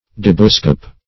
Search Result for " debuscope" : The Collaborative International Dictionary of English v.0.48: Debuscope \De"bu*scope\, n. [From the inventor, Debus, a French optician + -scope.]